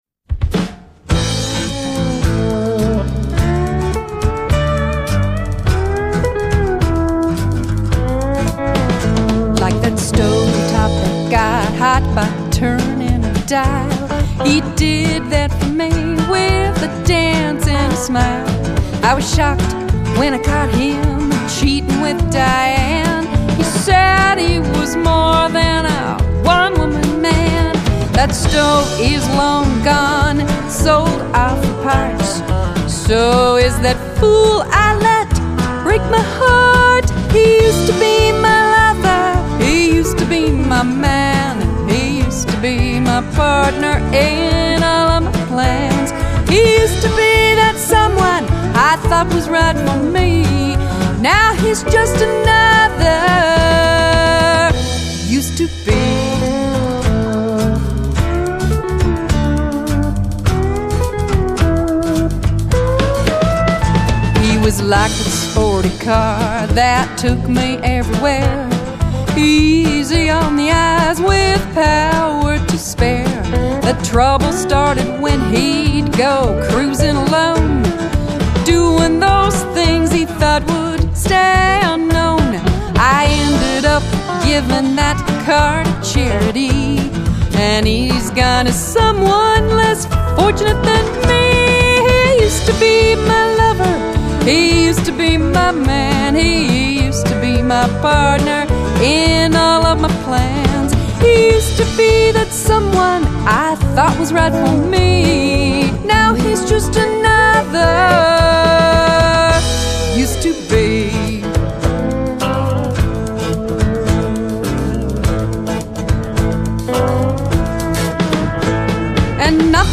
Final CD mix
Drums
MSA pedal steel, bass, Telecaster guitar